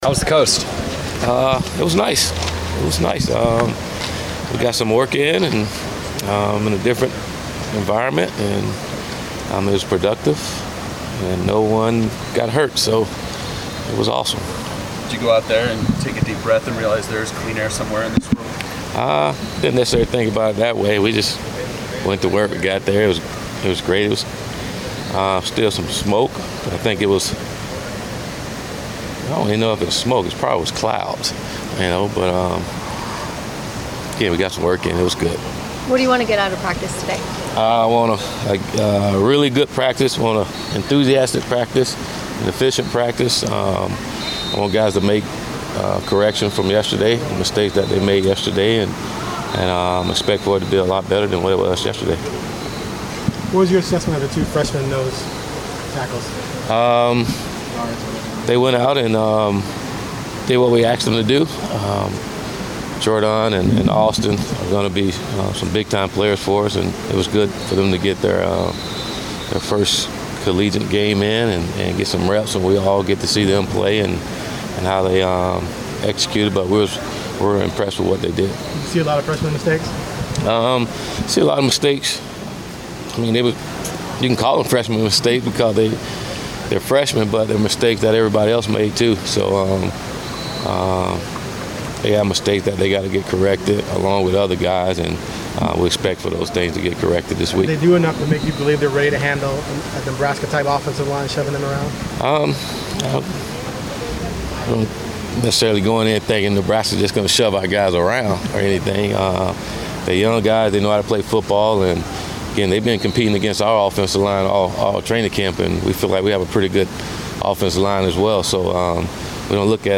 Willie Taggart Media Session 9-6-17